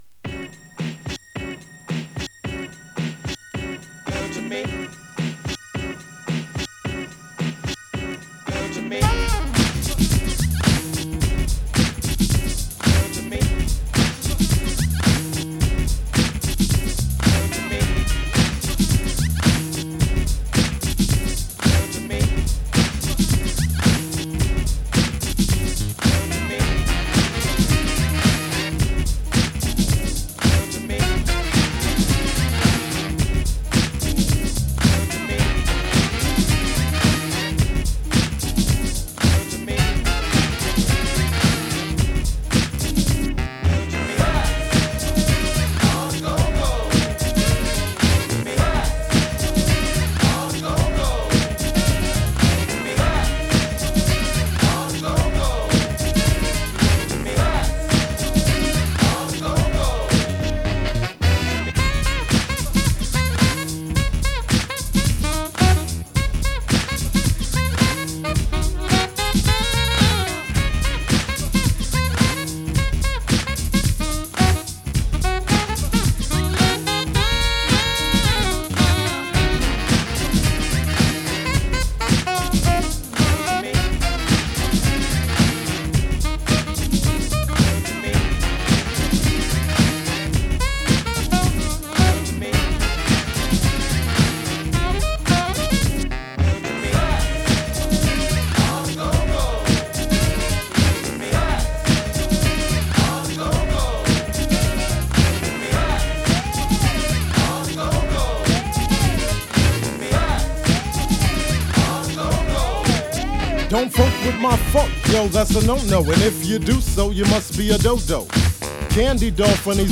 重 弾力 ファンキー パーティー HIP HOP バップ ジャズ
重く弾力のあるトラックにブロウするSAXがカッコいいパーティー・チューン！